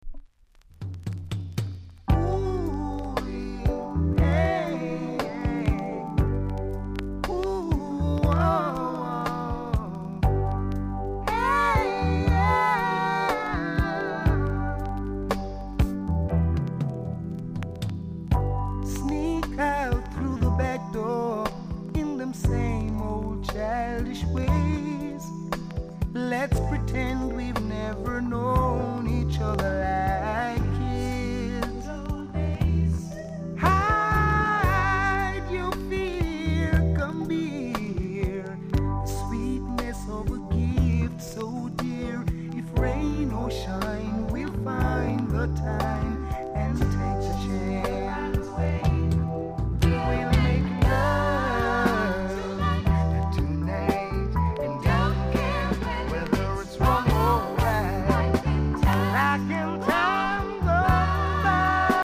コメント MELLOW ISLAND SOUL!!※裏面の後半で少しプチプチあります。